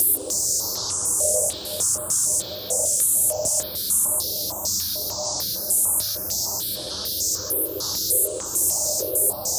STK_MovingNoiseD-100_02.wav